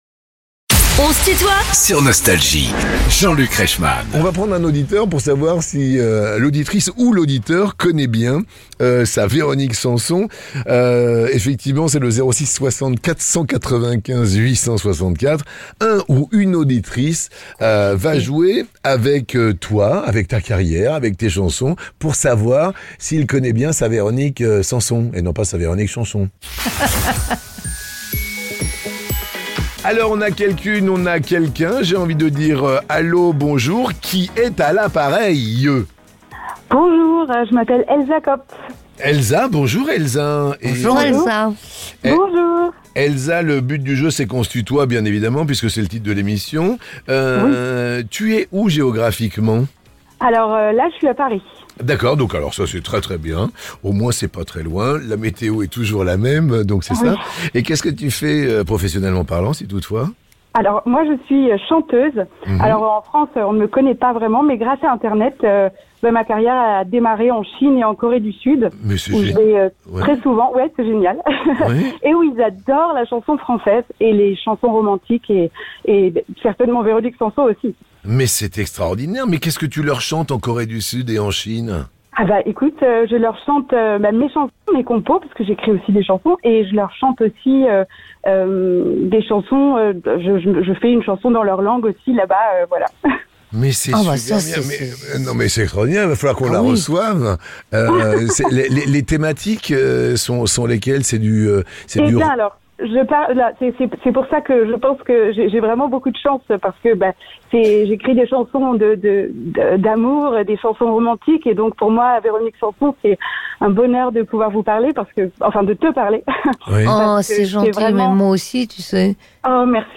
Véronique Sanson est l'invitée de "On se tutoie ?..." avec Jean-Luc Reichmann